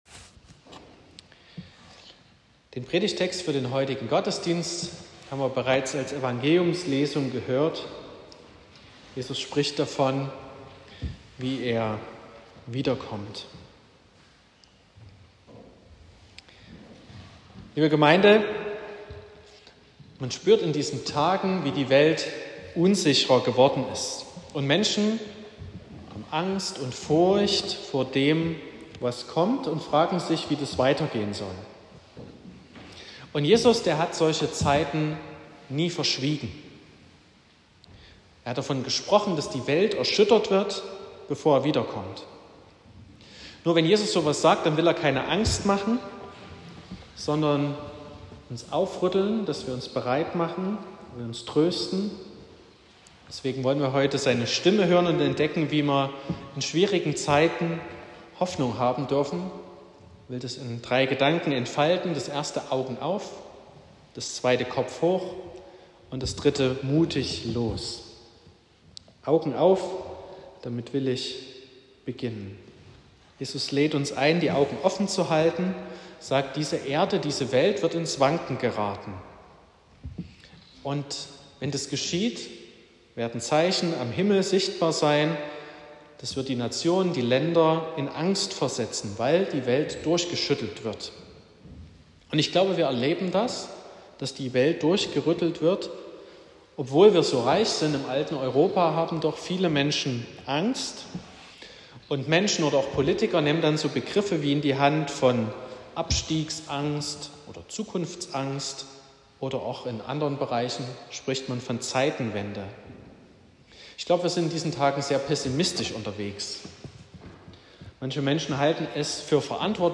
07.12.2025 – Gottesdienst
Predigt (Audio): 2025-12-07_Augen_auf__Kopf_hoch_und_mutig_los.m4a (7,9 MB)